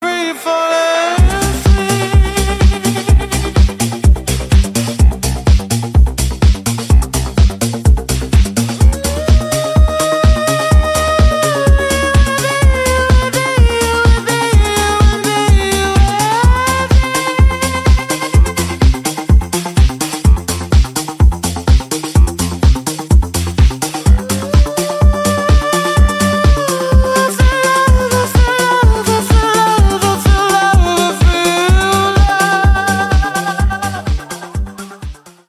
Tono para móvil